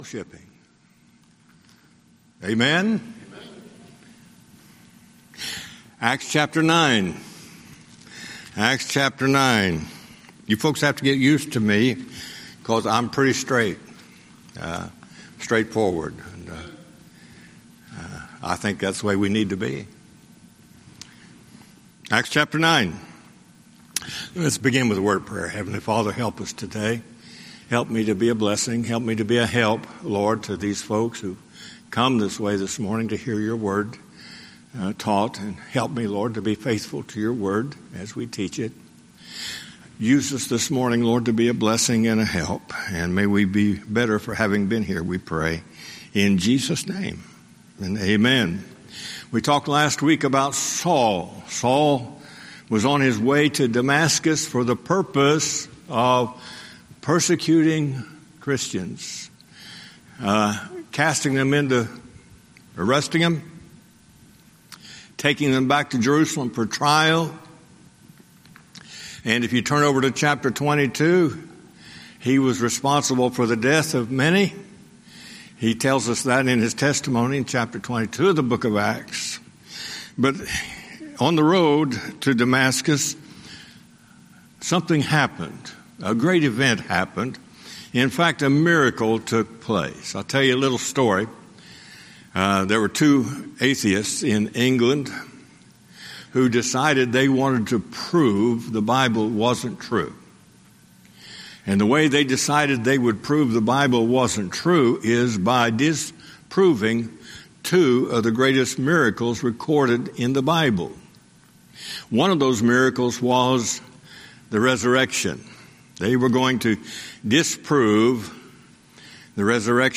Sunday School Recordings
Series: Guest Speaker